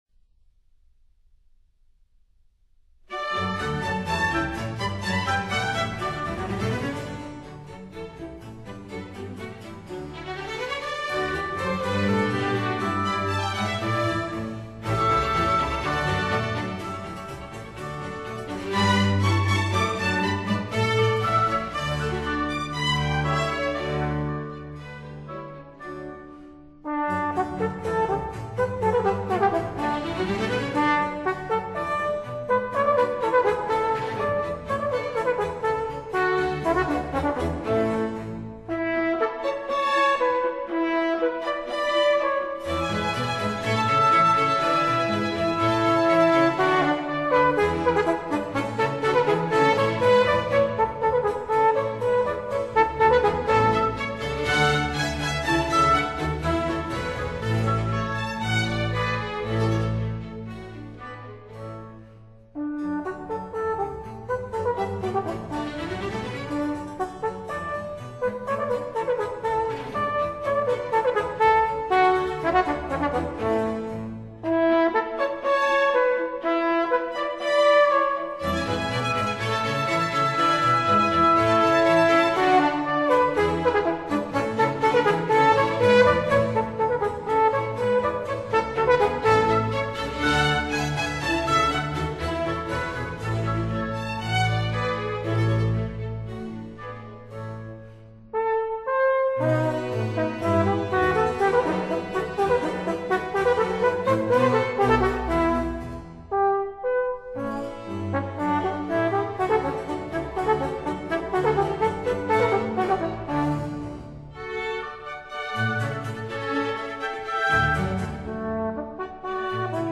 樂器介紹